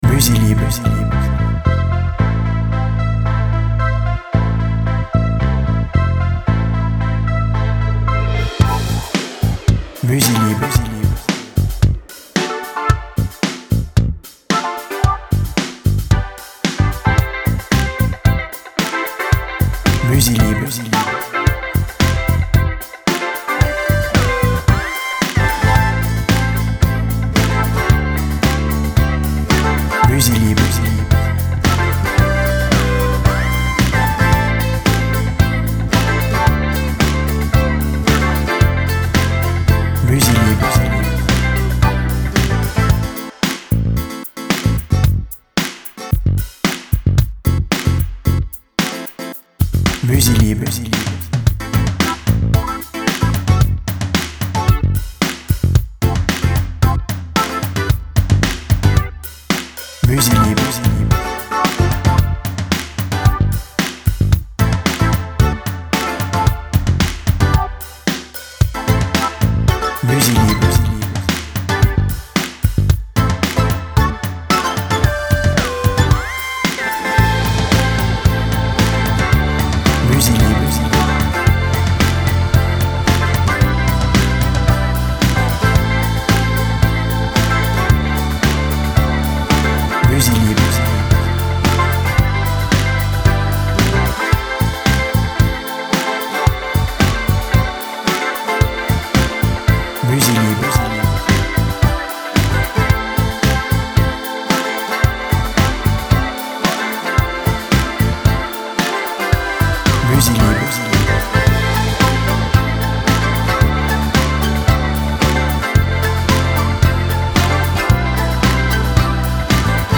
Musique pop electro/funky ultra efficace
par son ton moderne, festif et estival!
BPM Moyen